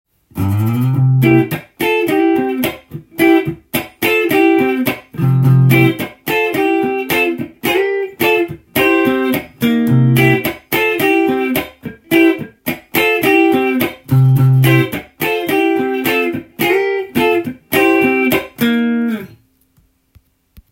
譜面通り弾いてみました
C7一発のコードでカッティングをしていますので
低音と高音を使い分けてストロークしています。
ベーシストがいるのかと勘違いするギターサウンドです。
ドラムのスネアがいるようなパーカッシブなサウンドを